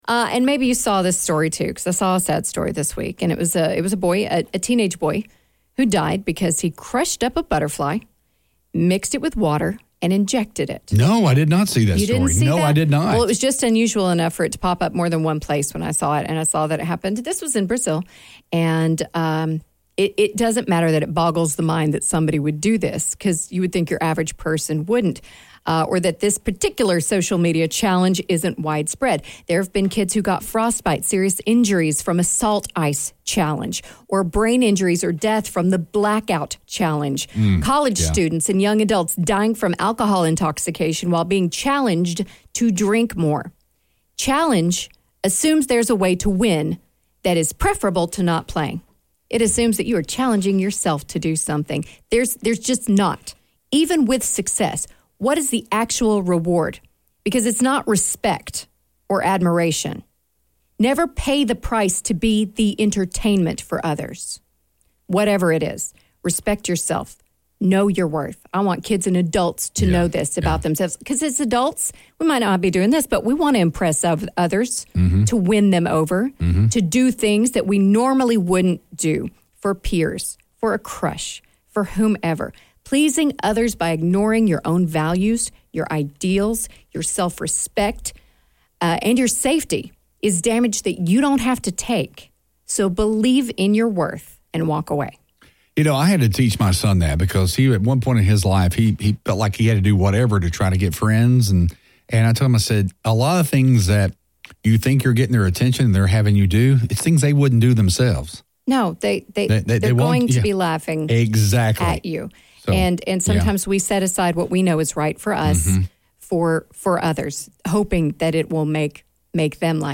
A little unpolished, a lot of positivity, and a lot of fun.